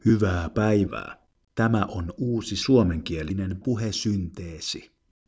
Yhdistelmälisenssi sisältää DialoQ Taivuttimen ja 4kpl aikuisten puheääniä.